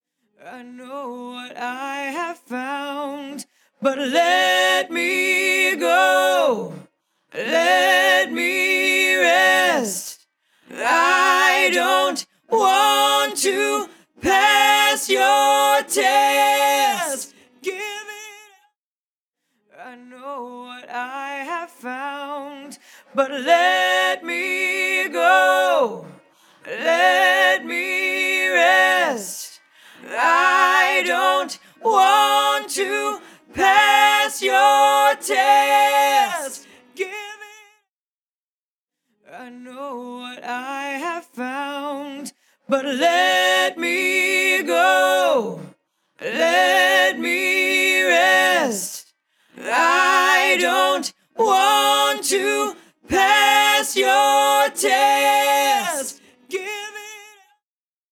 Invigorate | Vocals | Preset: Female Vox Bus Pusher
Invigorate-Female-Vox-Female-Vox-Bus-Pusher-CB.mp3